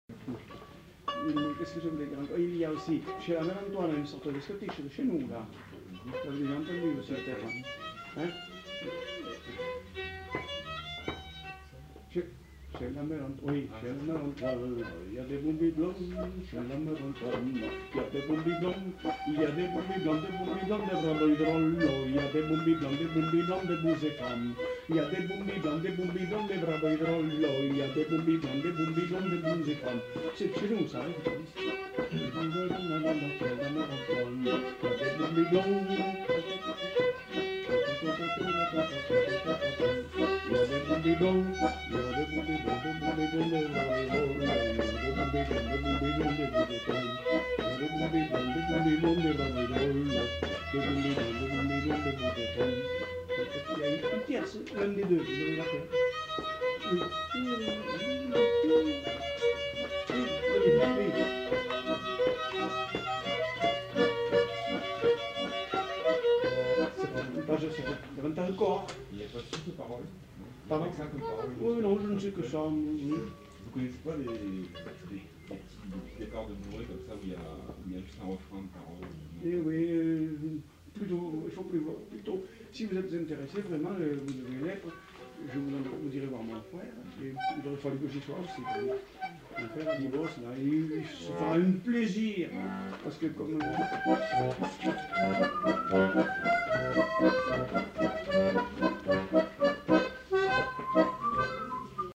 Aire culturelle : Agenais
Lieu : Foulayronnes
Genre : chanson-musique
Type de voix : voix d'homme
Production du son : chanté
Instrument de musique : accordéon diatonique ; violon